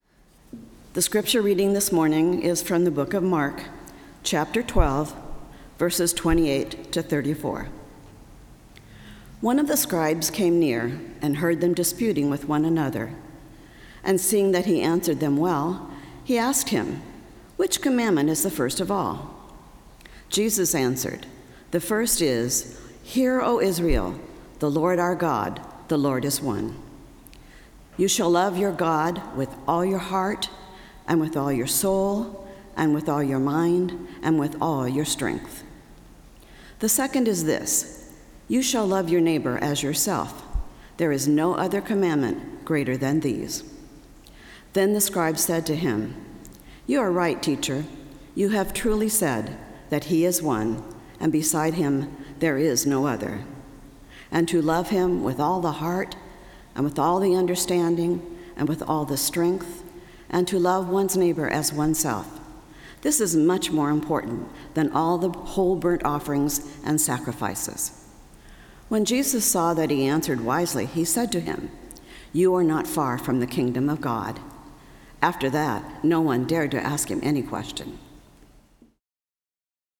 Service of Worship
Scripture Reading — Mark 12:28-34 (NRSV)